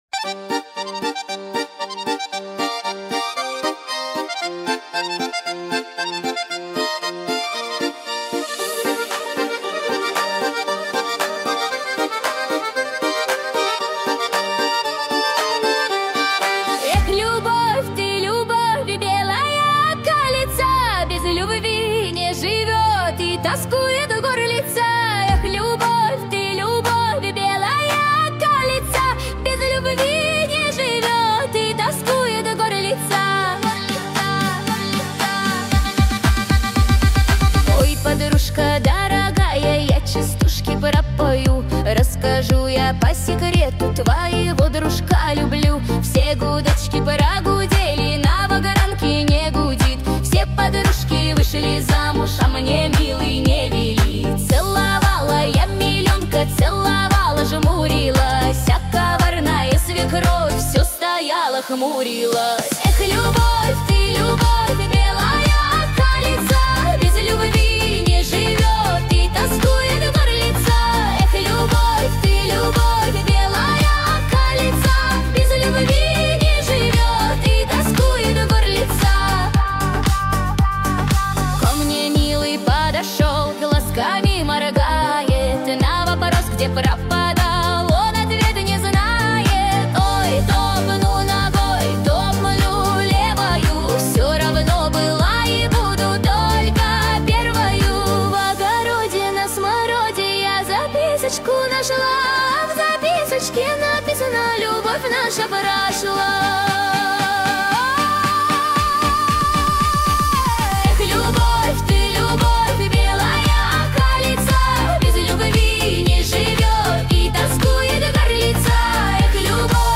13 декабрь 2025 Русская AI музыка 90 прослушиваний